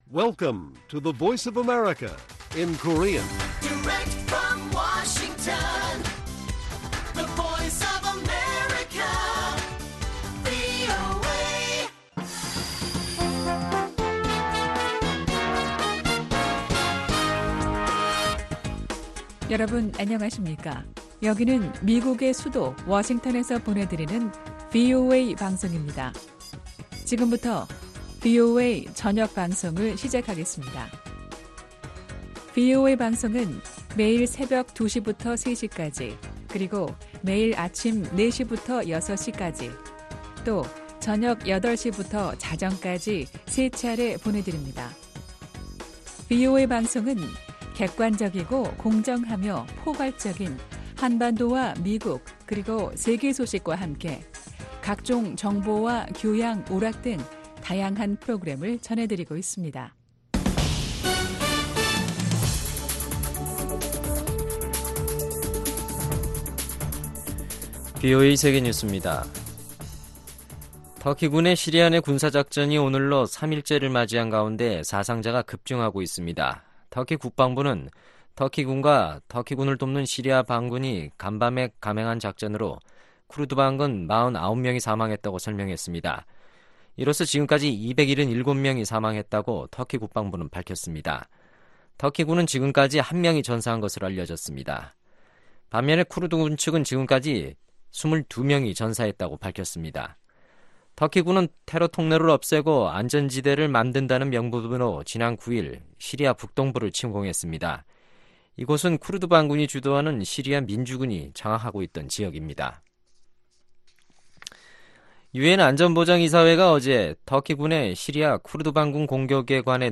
VOA 한국어 간판 뉴스 프로그램 '뉴스 투데이', 2019년 10월 11일 1부 방송입니다. 미-북 비핵화 실무협상 결렬 이후 미국 의원들은 최대 압박 복원을 위한 방안들을 제시하고 있으나 국제사회의 동참을 다시 이끌어내는 데 한계가 있을 것이라는 지적도 있습니다. 유럽 국가들이 유엔총회에서 다시 한 번 북한의 탄도미사일 발사를 규탄했습니다.